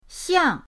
xiang4.mp3